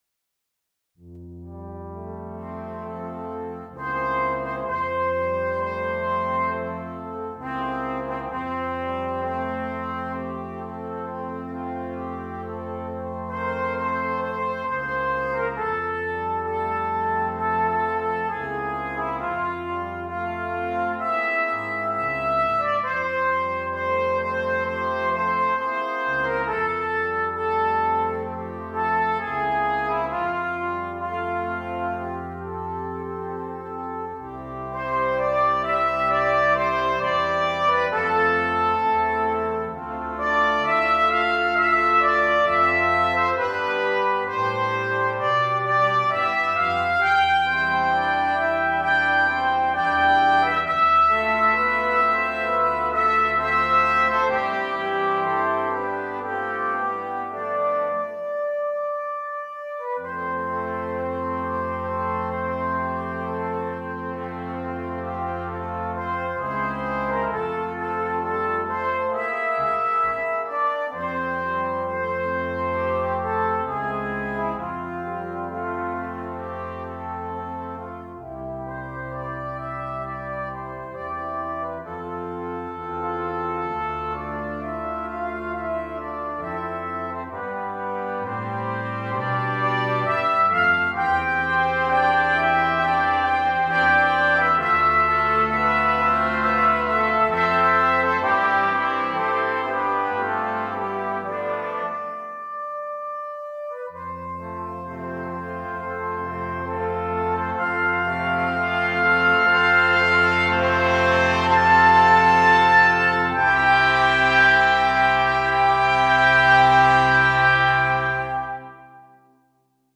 Brass Quintet and Solo Trumpet or Trombone